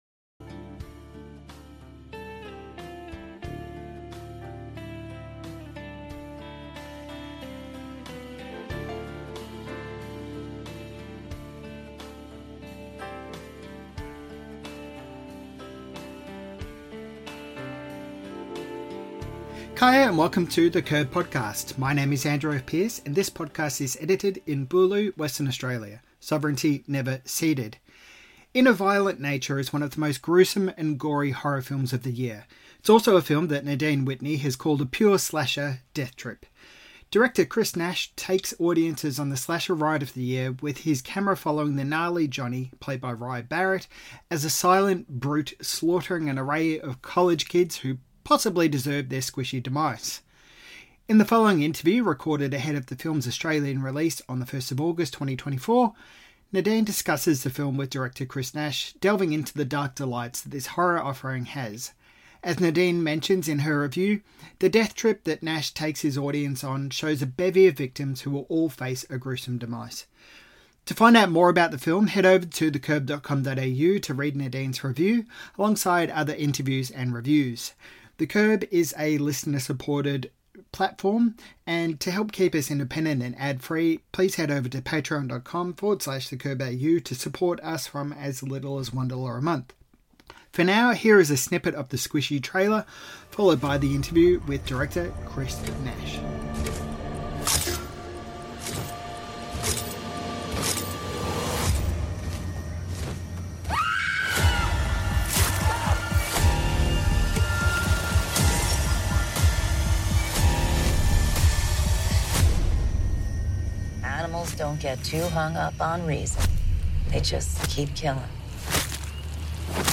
In the above interview